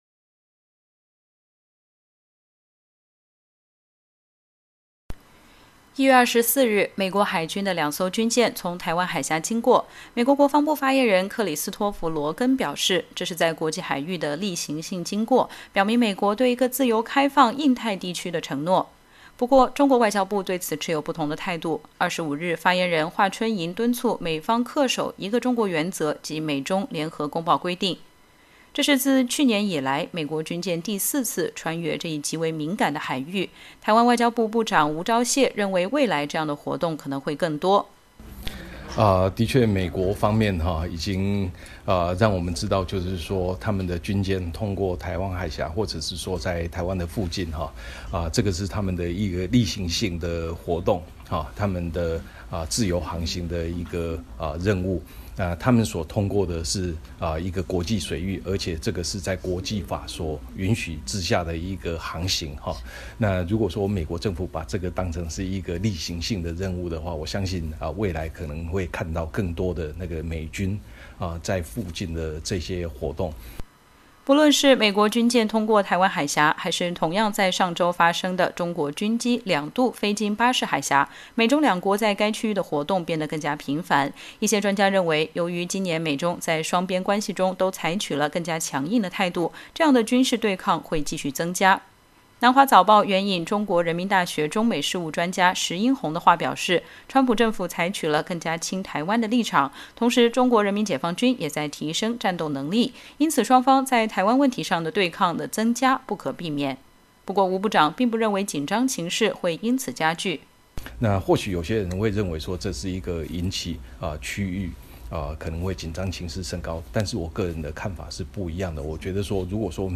台湾外交部部长吴钊燮28日对美国之音记者表示，台湾与美国的关系“比以往任何时候都更好”。他谈到三天前美国军舰航行通过台湾海峡一事，表示这并不会导致区域关系的紧张。